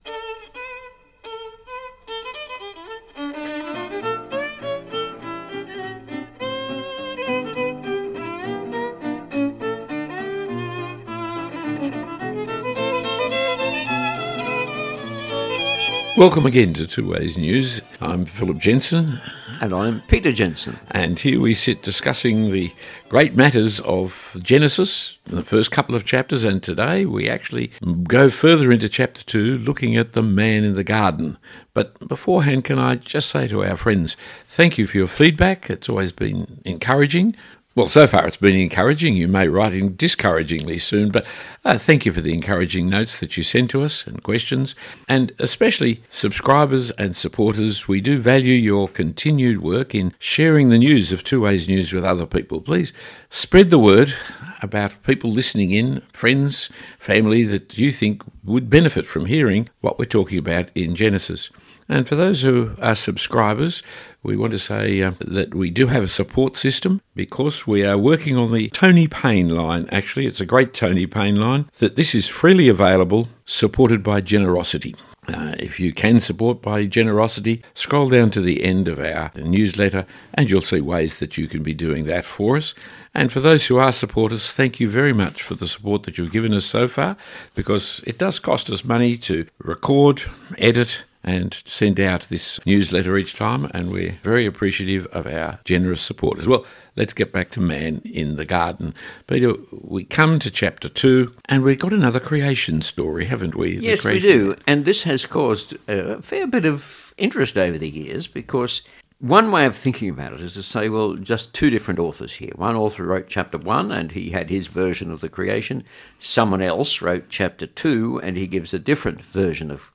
This podcast episode is a discussion of the movement of Genesis 1 to Genesis 2 where we look at man in the garden.